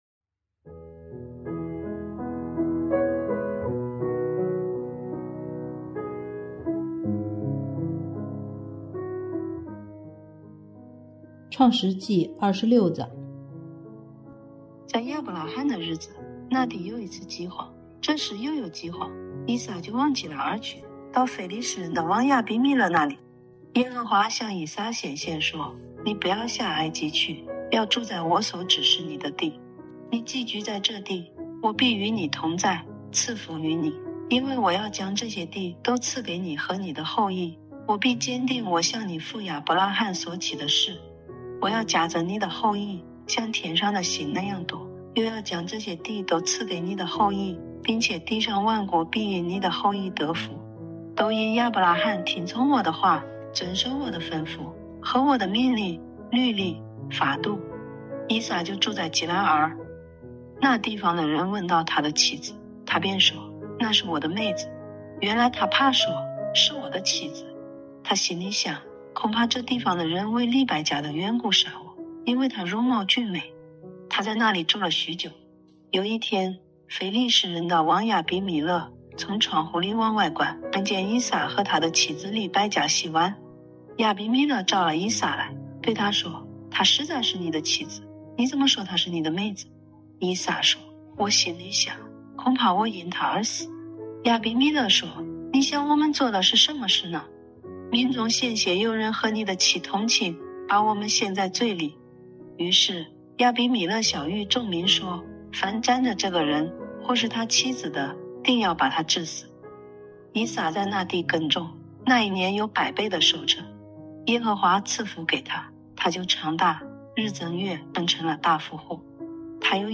读经马拉松 | 创世记26章(河南话)
语言：河南话